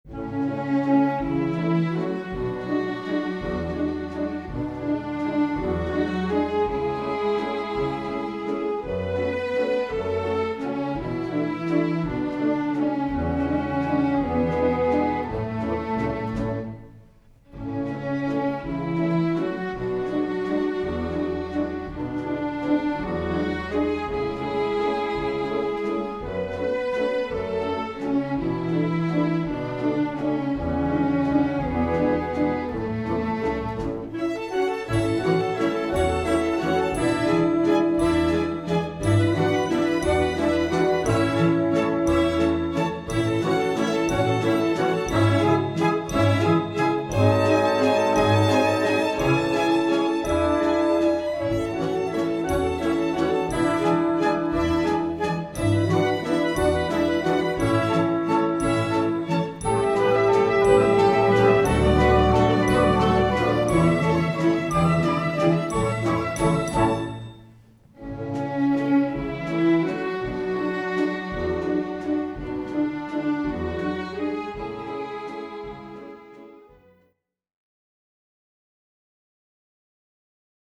Gattung: Für Schul- und Amateurorchester
Besetzung: Sinfonieorchester